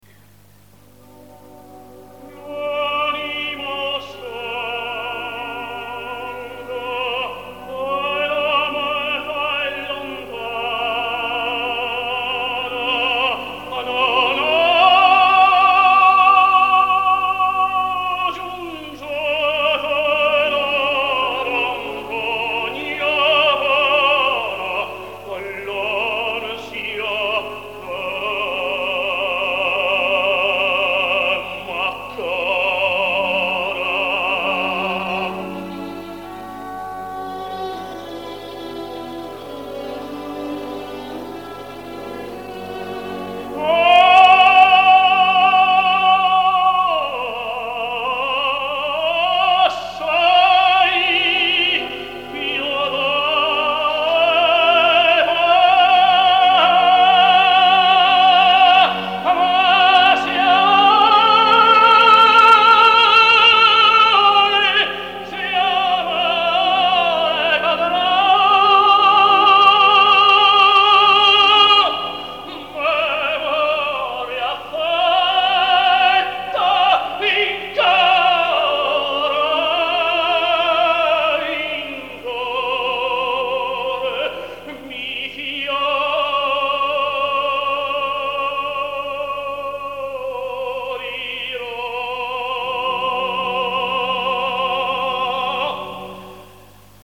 Великолепный тенор!